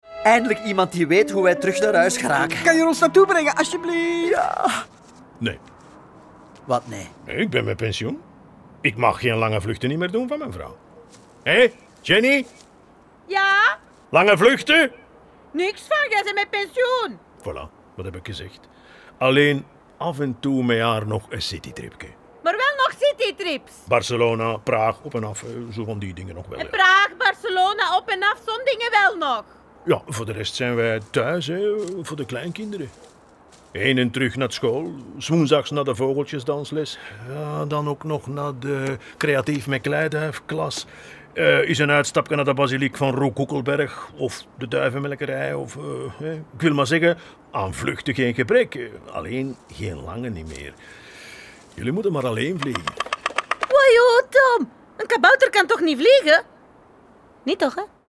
Kabouter Korsakov is een reeks luisterverhalen voor avonturiers vanaf 4 jaar.